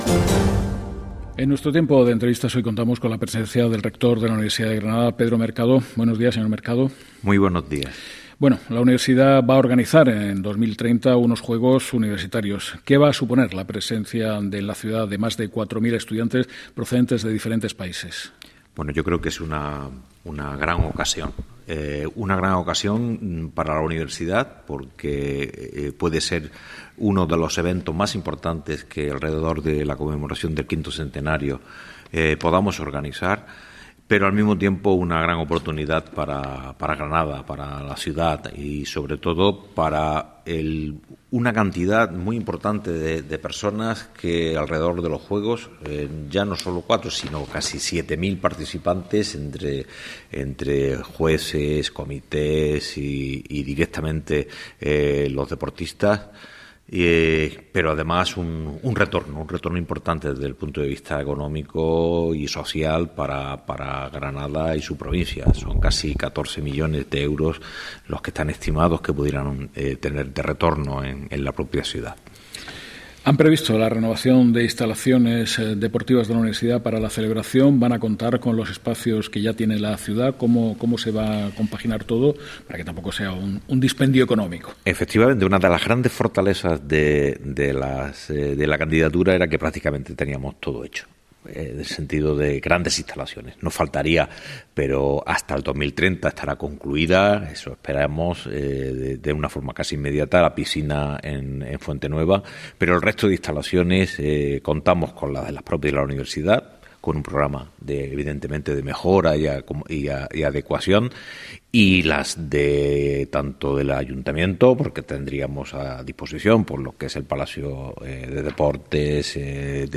«Radio 5 de RNE» entrevista al rector de la Universidad de Granada, Pedro Mercado Pacheco, en relación a la designación de ciudad de Granada como sede en el año 2030 de los Juegos Europeos Universitarios EUSA Game.